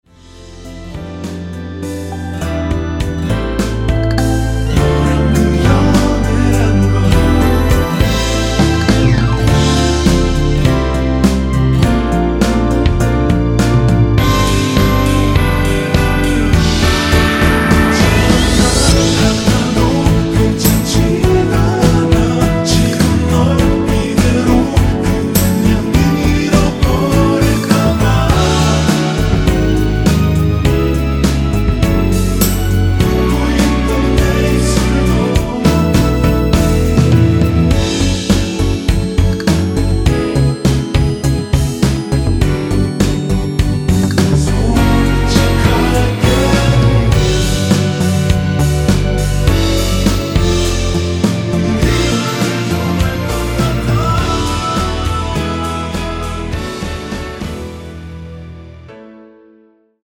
원키에서(-2)내린 코러스 포함된 MR입니다.
앨범 | O.S.T
앞부분30초, 뒷부분30초씩 편집해서 올려 드리고 있습니다.